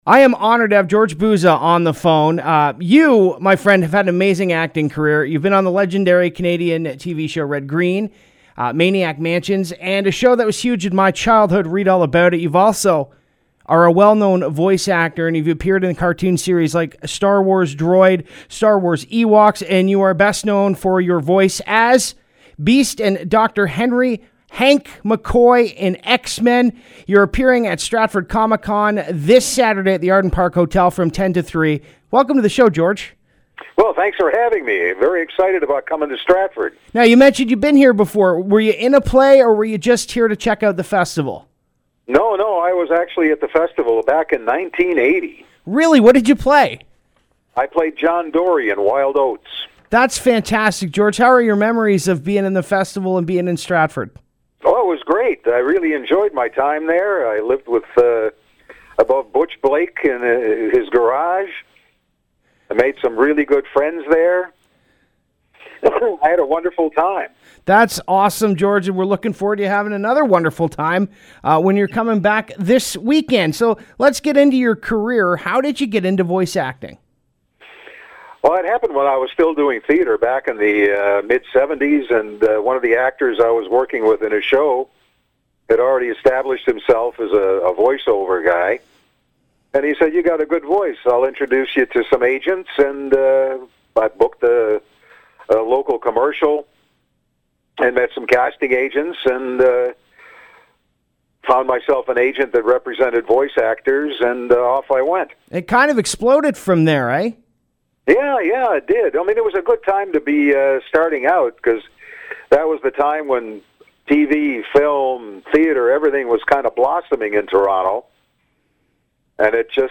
He will be at Comic Con this Saturday at The Best Western Arden Park from 10a to 3p!! Here is the interview